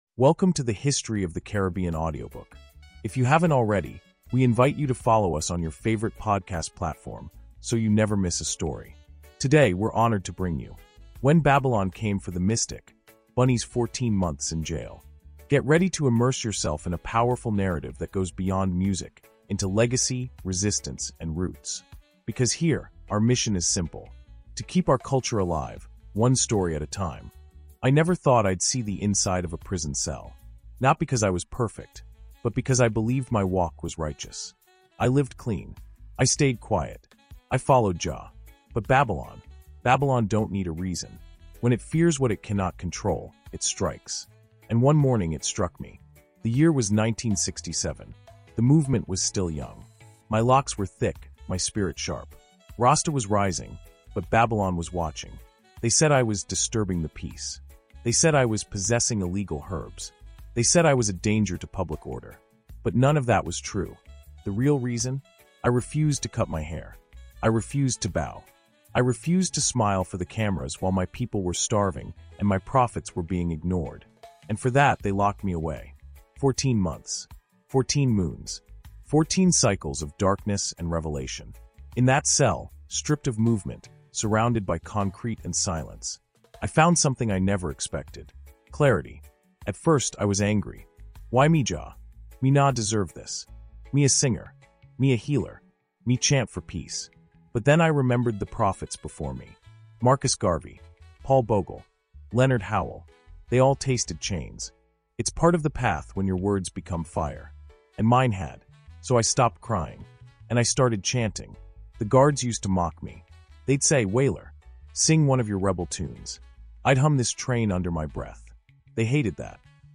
In this gripping and meditative episode, Bunny Wailer shares the untold story of his 14 months behind bars.
Through powerful narration, Bunny walks us through the spiritual awakening that took place in a prison cell, revealing how even chains couldn’t break his message.